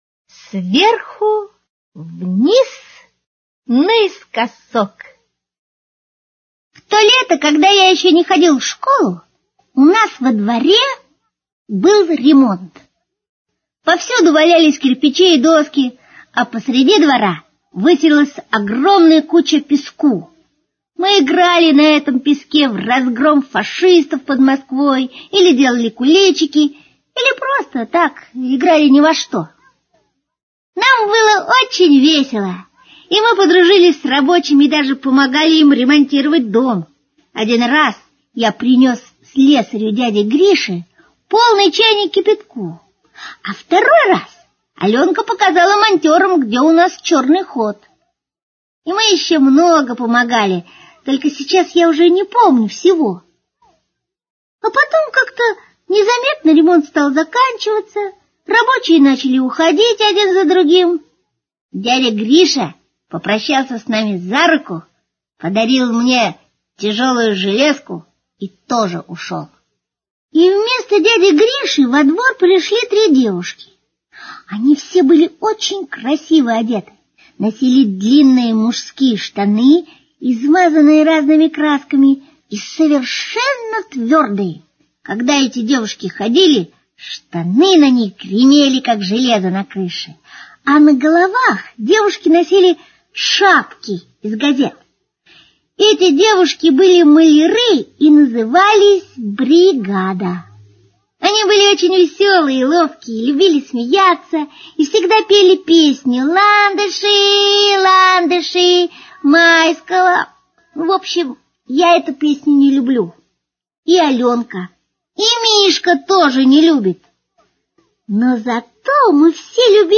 Слушайте онлайн Сверху вниз наискосок - аудио рассказ Драгунского В.Ю. Летом у Дениски во дворе был ремонт.